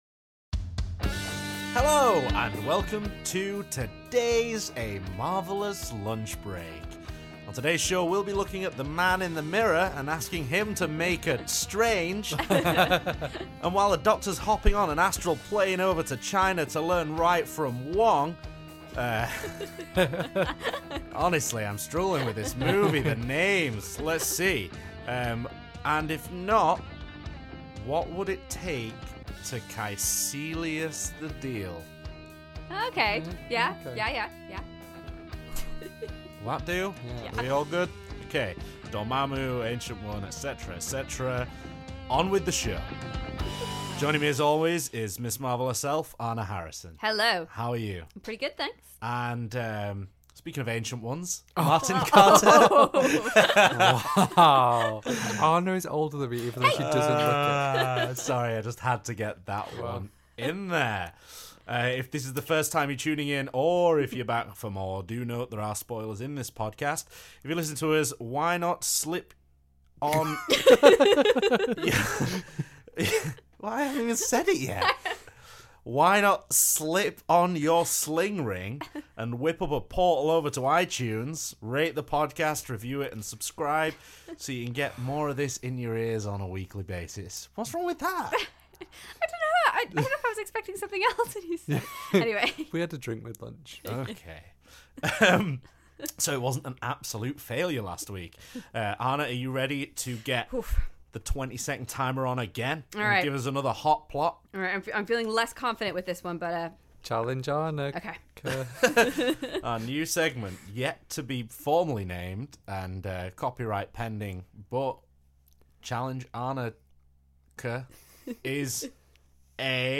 Sit back and enjoy another Marvel filled lunch break with your hosts